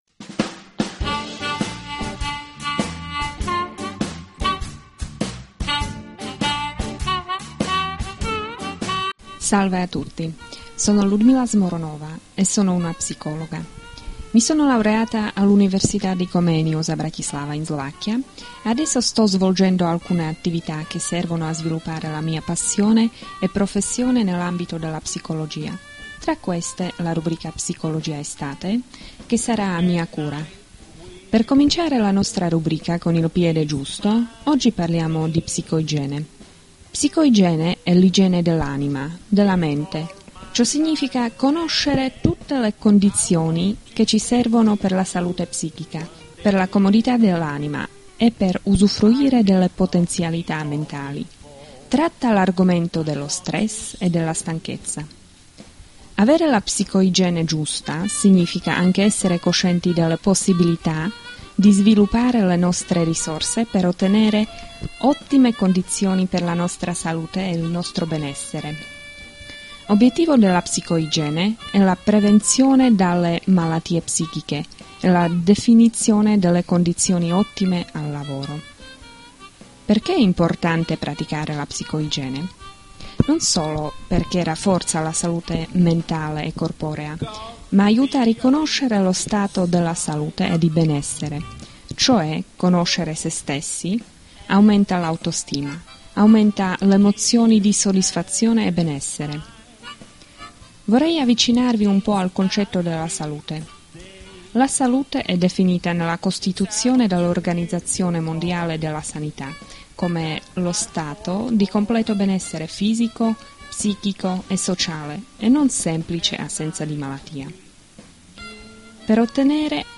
Commento musicale